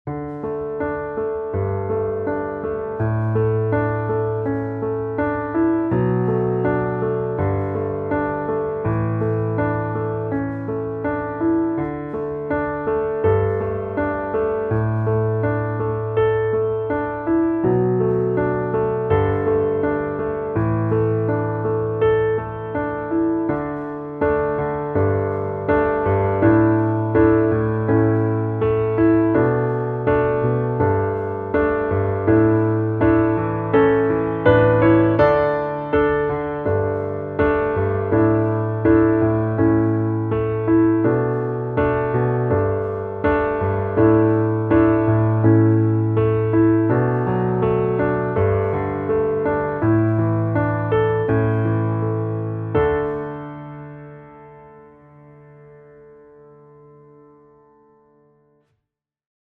underscores for contemporary worship